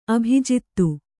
♪ abhijittu